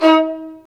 Index of /90_sSampleCDs/Roland LCDP13 String Sections/STR_Violas Marc/STR_Vas3 Marcato